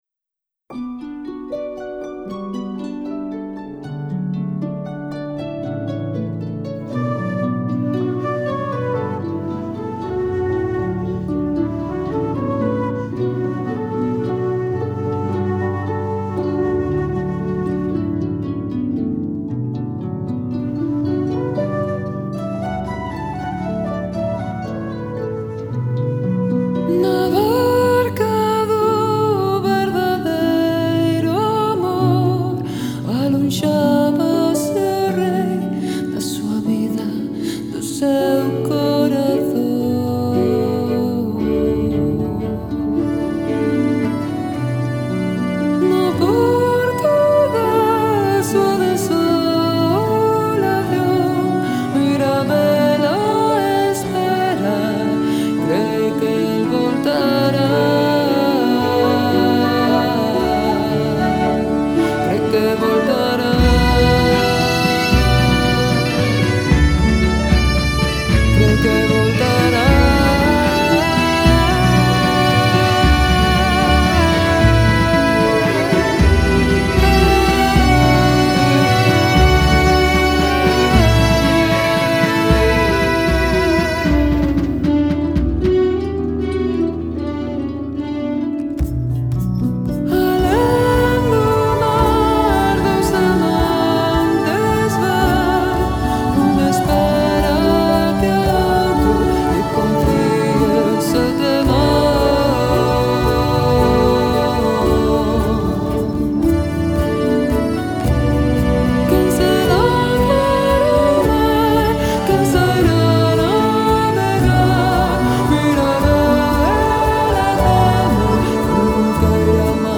Folk / Tradicional / World music